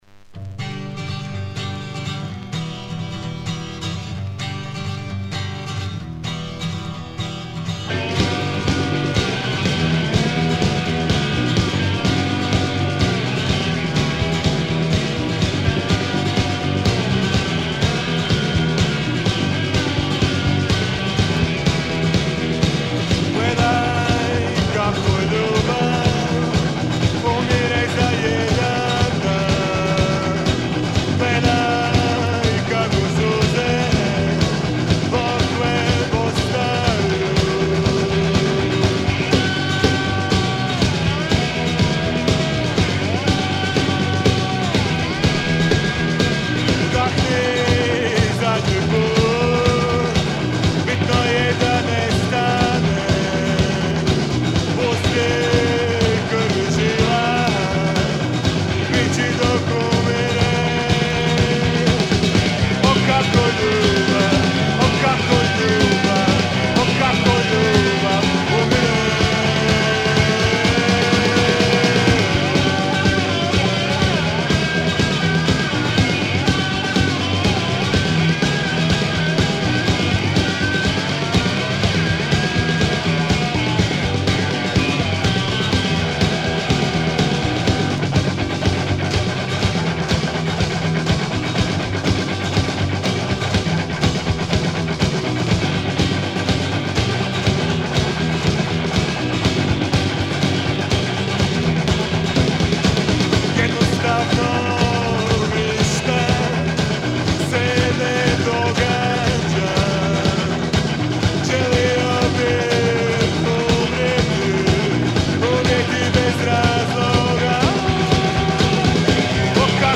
guitar and voice
drums
bass